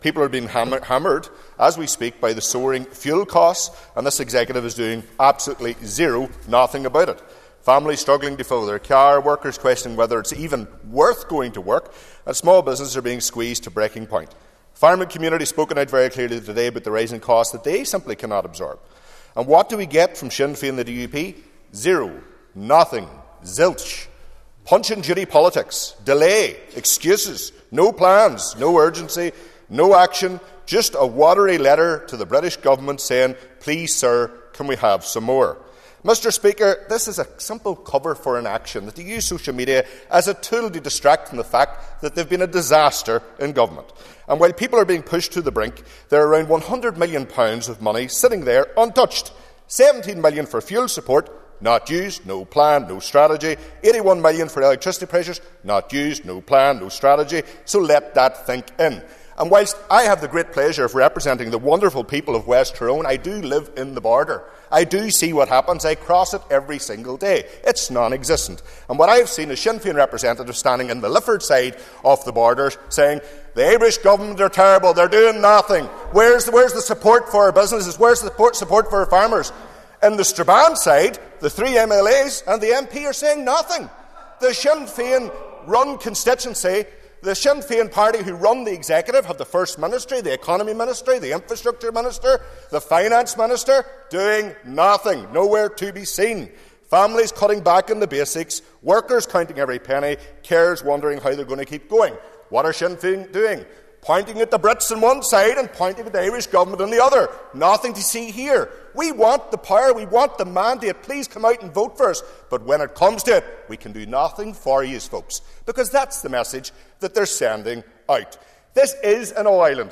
Speaking in the Assembly today, Daniel McCrossan claimed there are around a hundred million pounds available in supports to the executive, they are not being used because there is no plan.